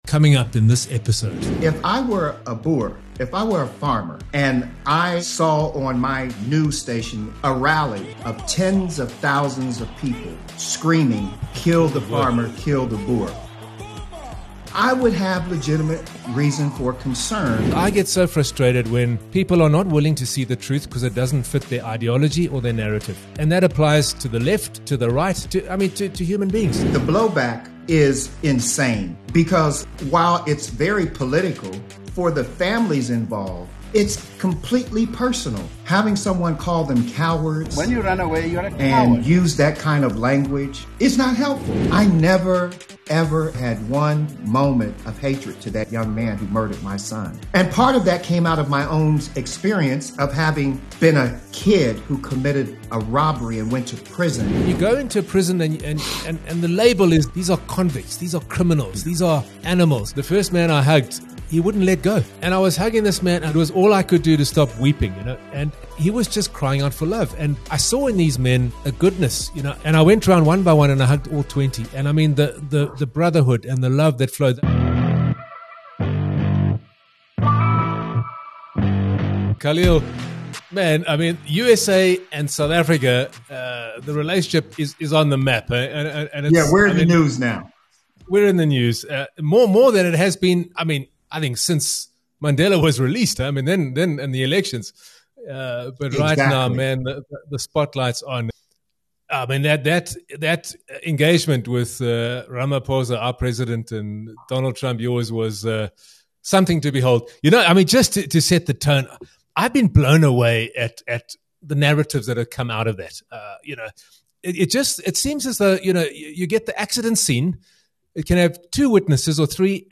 This conversation dives into South Africa's hidden crisis, racial politics, and what it takes to move from pain to reconciliation.